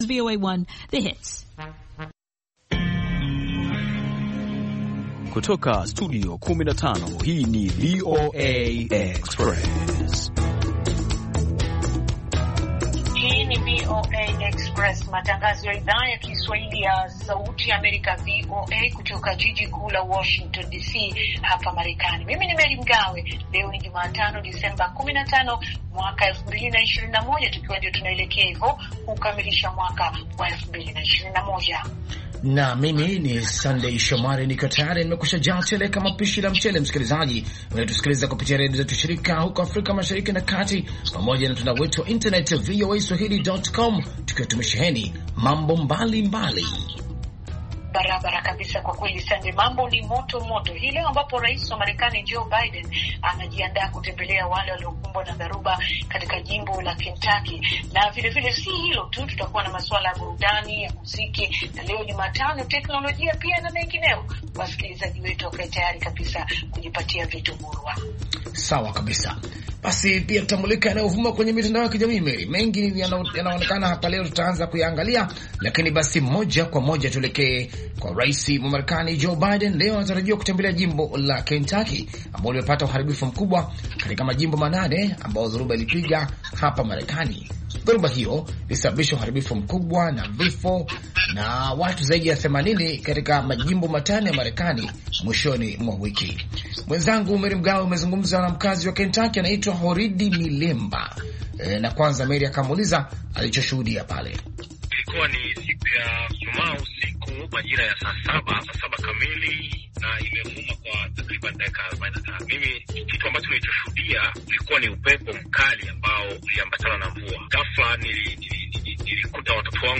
VOA Express ni matangazo mapya yenye mwendo wa kasi yakiangalia habari mpya za mchana na maelezo ya maswala yanayohusu vijana na wanawake. Matangazo haya yanafuatilia habari zilizojitokeza nyakati za mchana na ripoti za kina za habari ambazo hazisikiki sana katika matangazo mengineyo. VOA Express pia inafuatilia kwa karibu sana maswala yanayovuma katika mitandao ya kijamii kama sehemu moja muhimu ya upashanaji habari siku hizi.